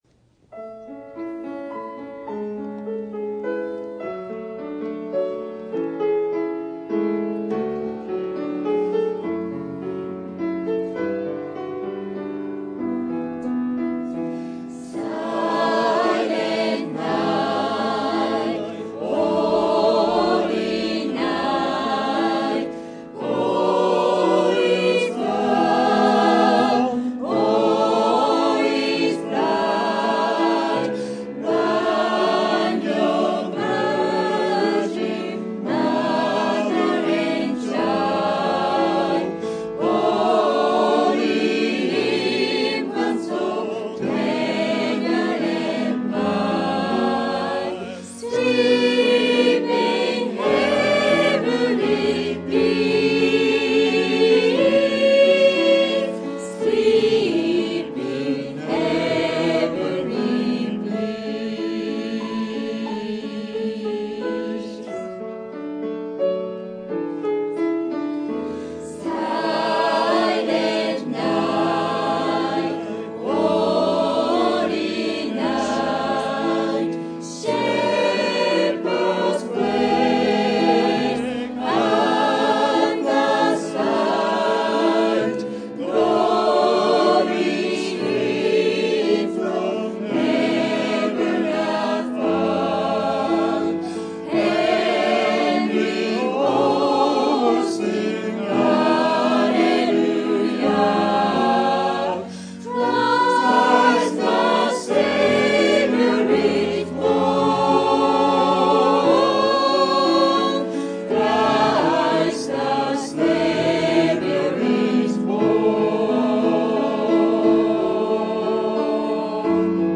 2009.12 ◆キャンドルサーヴィス (12/24)
ゴスペルクワイヤー賛美